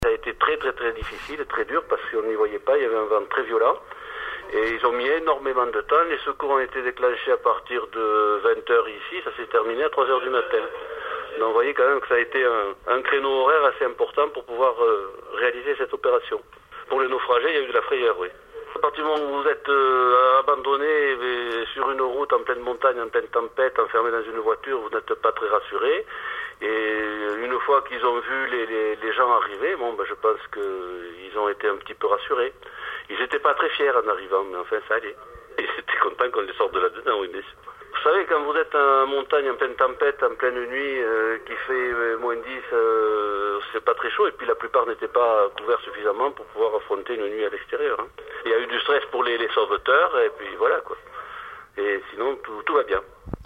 C’est ce qui est arrivé à ces automobilistes pris dans une tempête de neige en montagne, à un col qui aurait probablement dû être fermé plus tôt à la circulation. Comme le raconte un de ceux qui sont intervenus pour leur venir en aide, ils ont eu bien froid !
Ici, comme souvent en français, on va jusqu’à l’utiliser trois fois de suite, en le répétant très vite.